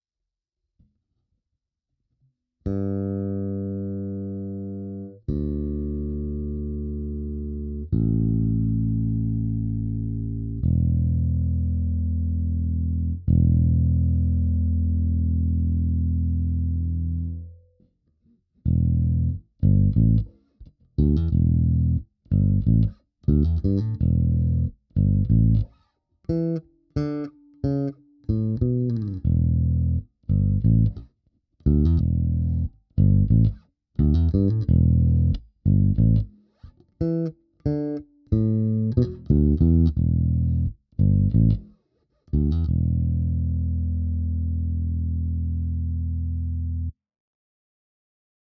Naskytla se mi příležitost přímého porovnání na mém setupu. Jedná se o 55-02 vs. Stingray Special 4H, stejné stáří strun, hmatník obojí palisandr.
Lakland 55-02 kobylka HB